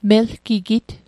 Pronunciation Guide: mel·ki·git Translation: He/she is solidly built